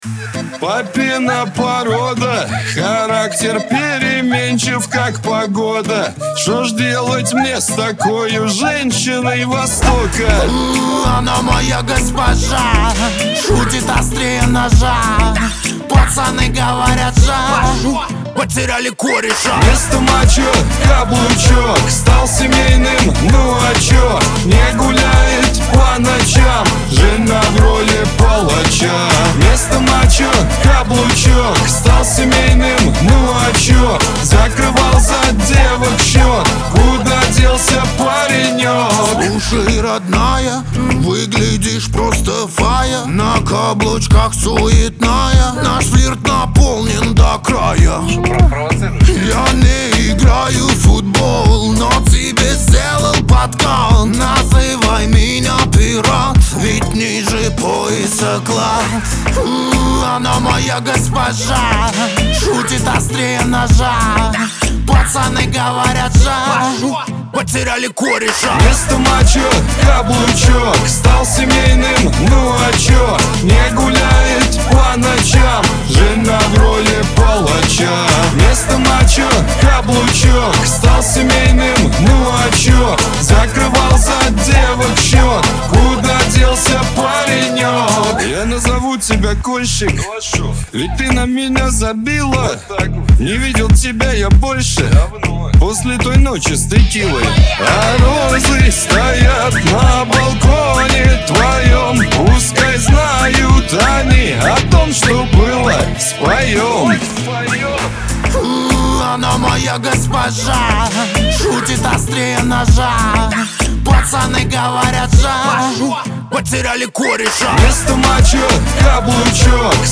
Лирика
эстрада
ХАУС-РЭП
дуэт